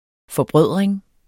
Udtale [ fʌˈbʁœðˀʁeŋ ]